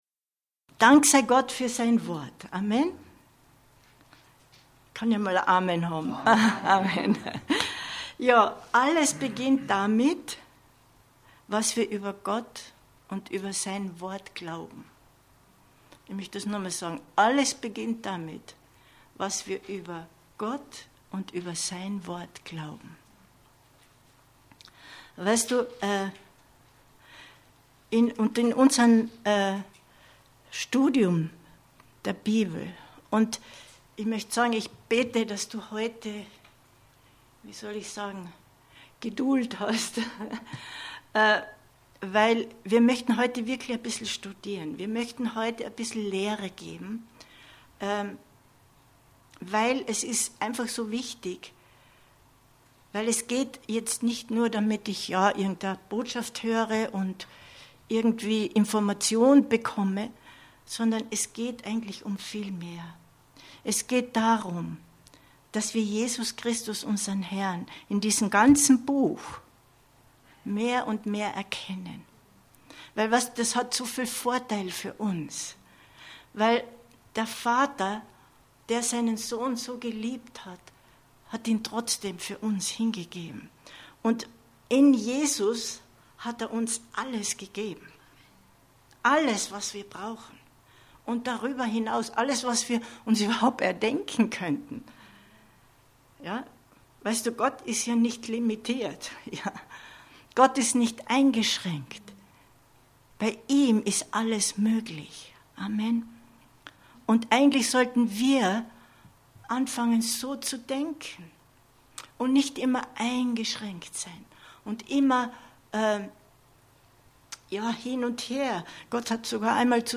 Deine neue Identität in Christus erkennen 13.11.2022 Predigt herunterladen